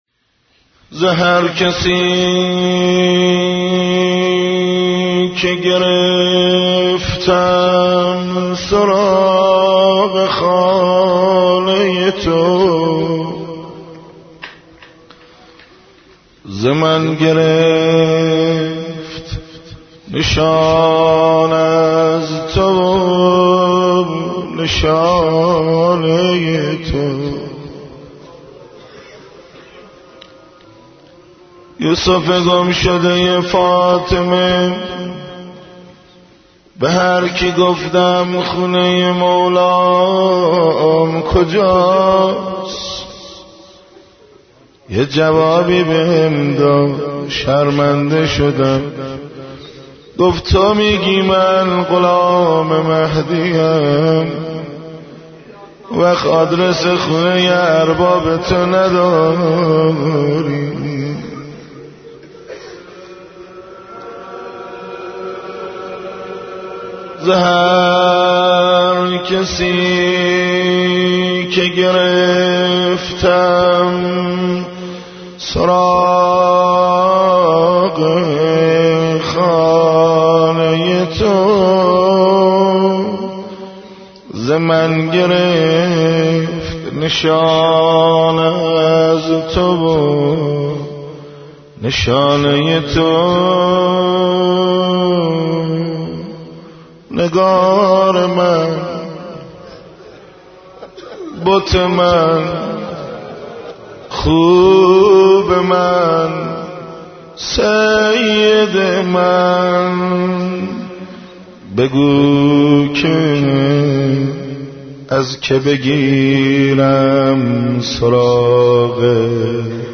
مناجات با امام زمان(عج)